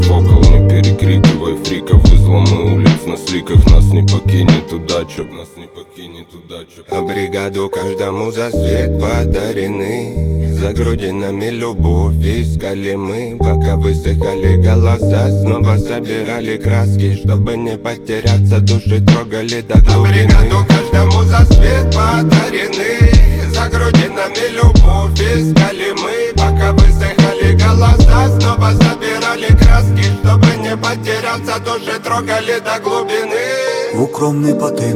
Hip-Hop in Russian Hip-Hop Rap